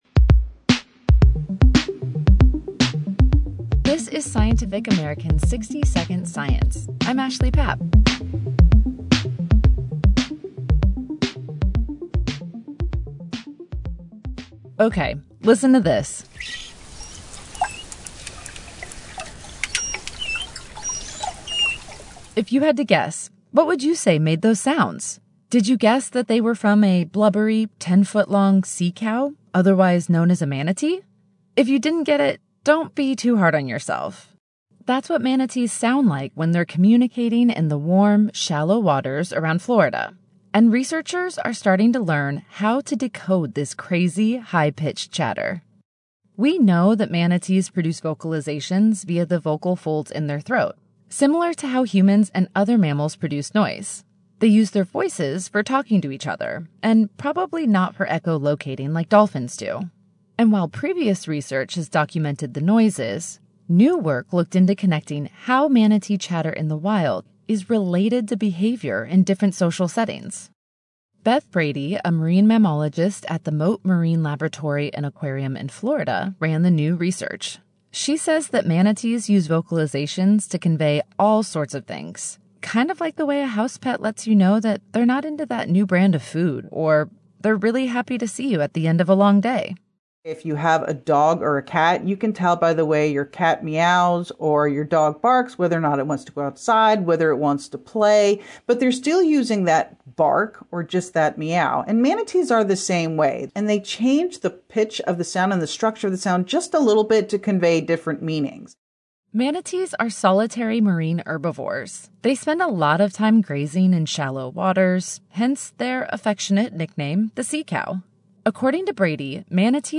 60SS-02-Manatee-Talk_Final.mp3